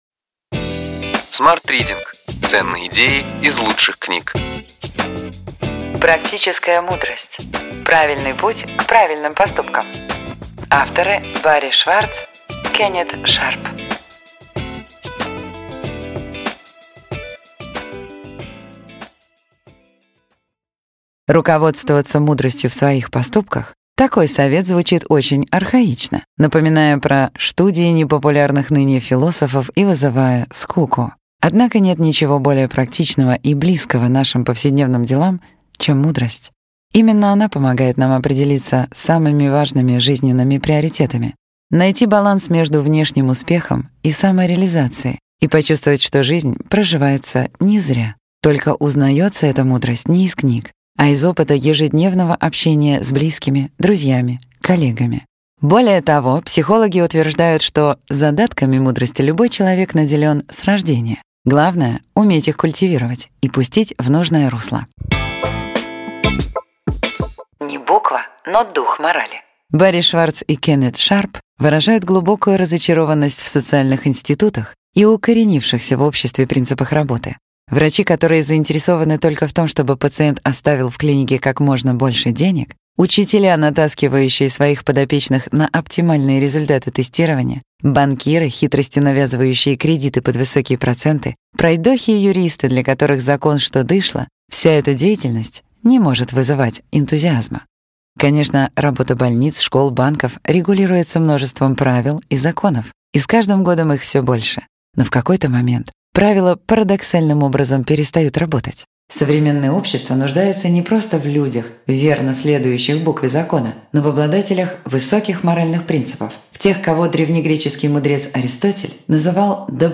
Аудиокнига Ключевые идеи книги: Практическая мудрость. Правильный путь к правильным поступкам.